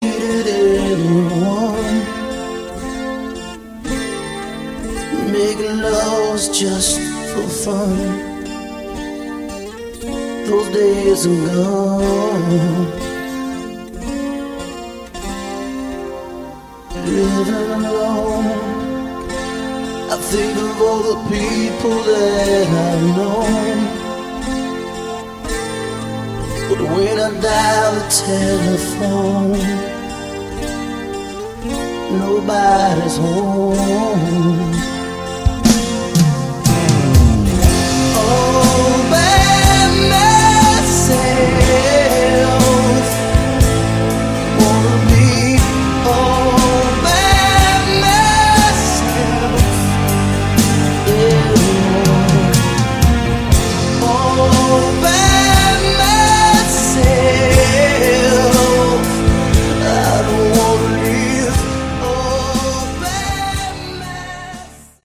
Category: Hard Rock
lead vocals
guitar
bass
drums